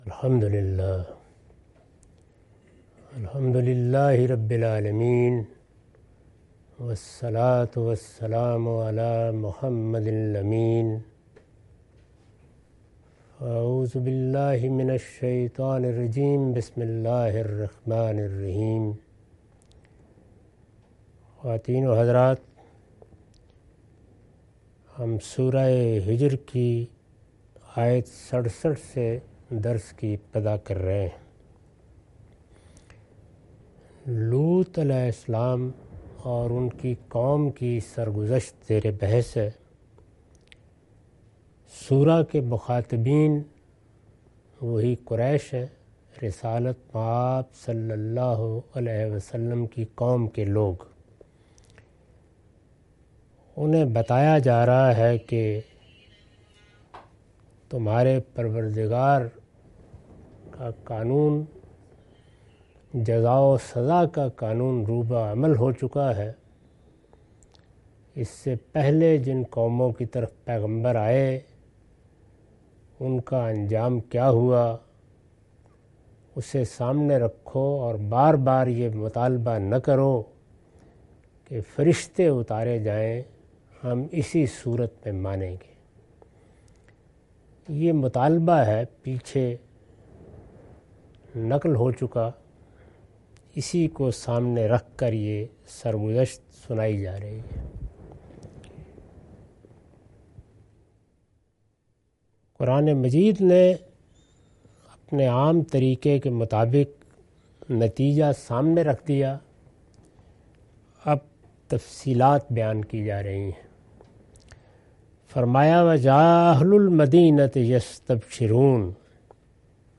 Surah Al-Hijr- A lecture of Tafseer-ul-Quran – Al-Bayan by Javed Ahmad Ghamidi. Commentary and explanation of verses 67-77.